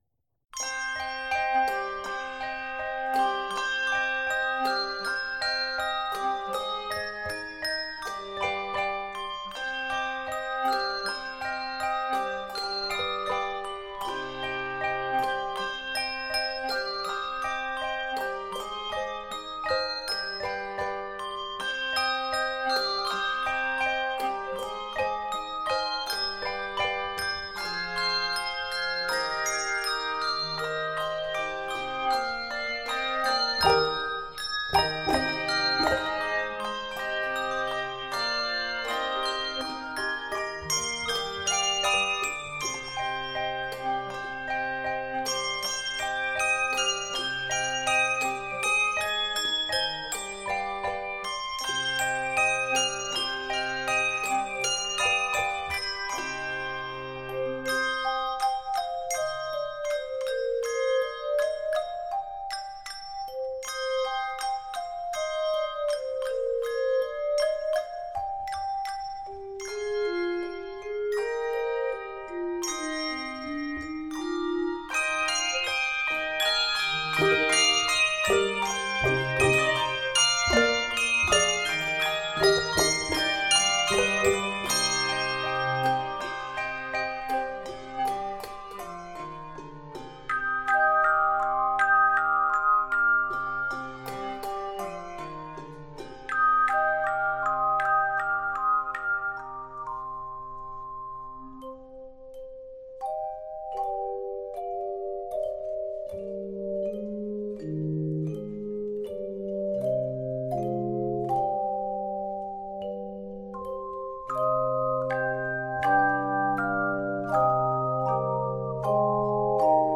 Key of c minor.